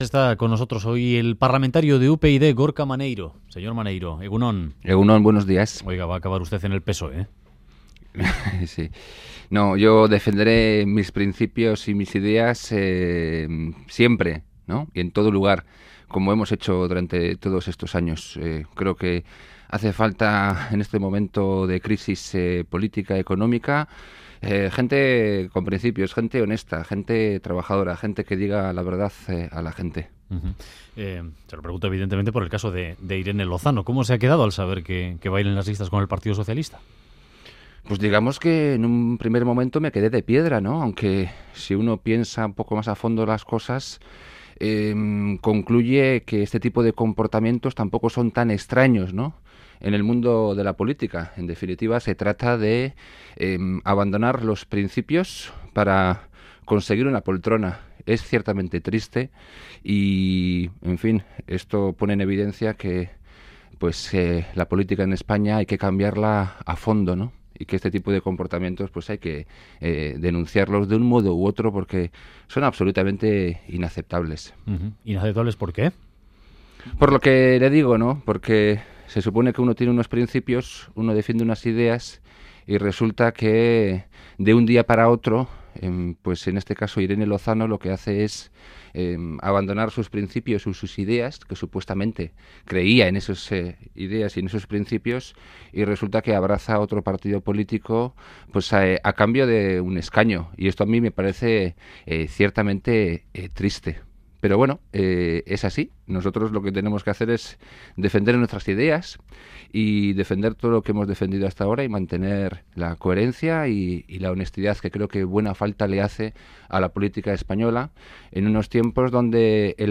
Radio Euskadi BOULEVARD 'El Concierto Económico es de derechas, reaccionario y conservador' Última actualización: 20/10/2015 10:20 (UTC+2) En entrevista al Boulevard de Radio Euskadi, el parlamentario de UPyD, Gorka Maneiro, ha reiterado la intención de su partido de suprimir las Diputaciones y el Concierto Económico, un sistema que ha calificado como muy de derechas, reaccionario y conservador. Ha insistido en la necesidad de una reforma constitucional y la creación de un estado federal, y ha criticado con dureza a la exdiputada de UPyD, Irene Lozano, por pasarse al PSOE.